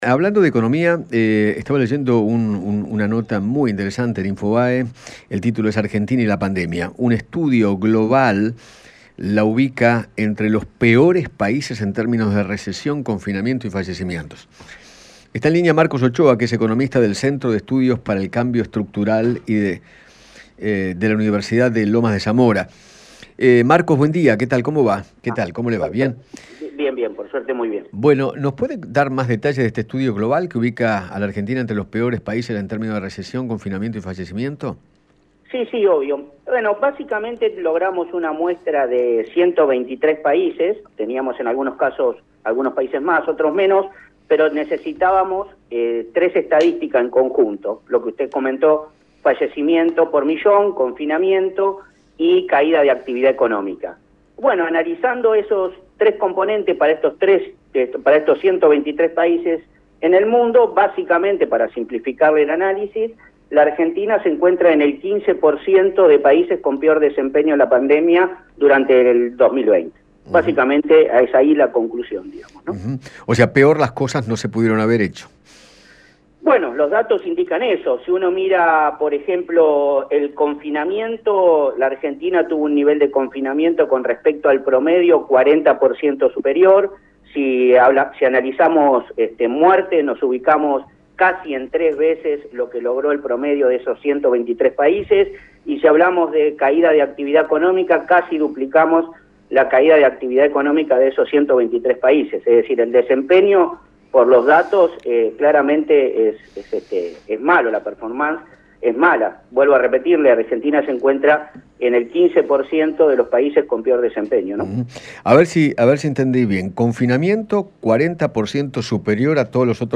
8economista.mp3